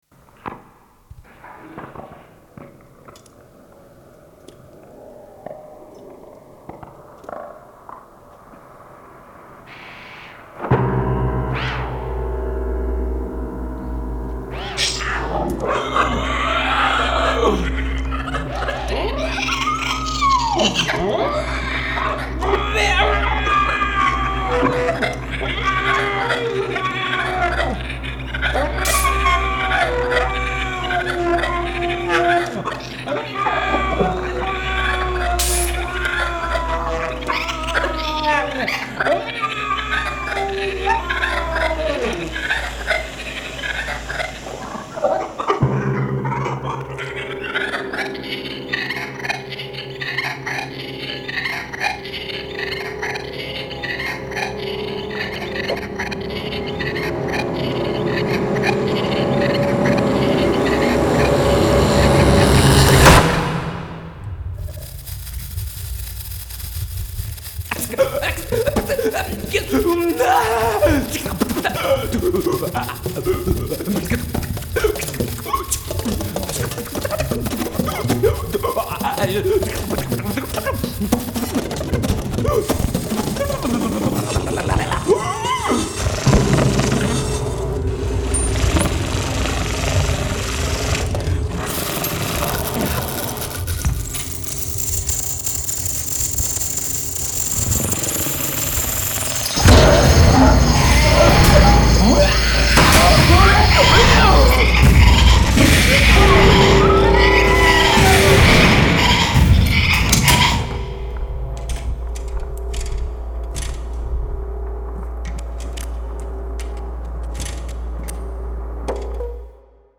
laptop, processed tapes, analog synth, radio
feedback and acousitc sax, resonant/feed-drums